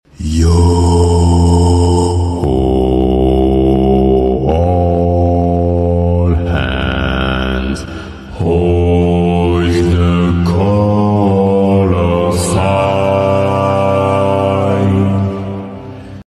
ههههههه sound effects free download